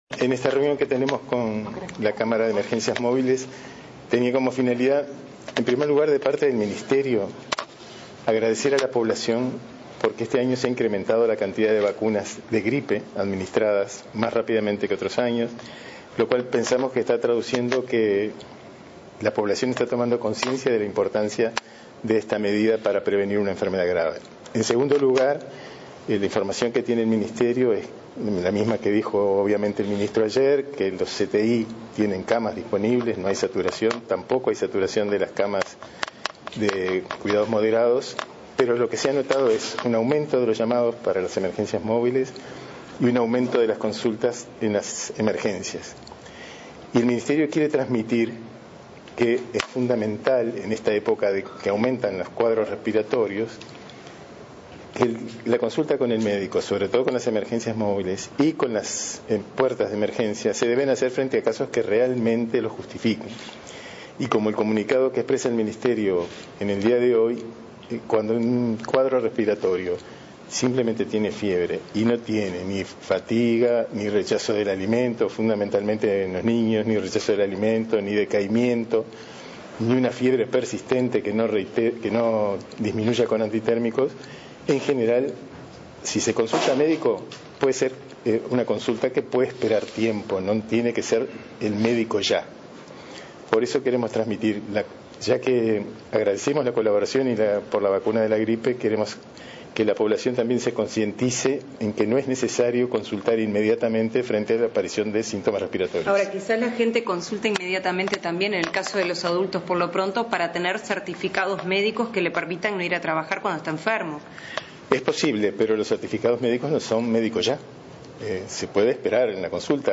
El director de Salud, Jorge Quian subrayó que ante el aumento de demanda en las puertas de emergencia de los centros de salud por cuadros respiratorios por el comienzo de los fríos, se priorizará la atención de los casos más graves. Subrayó que no es necesario consultar de inmediato ante síntomas respiratorios y que se puede esperar en casos banales. Si bien están circulando los virus gripales, destacó la adhesión a la vacuna.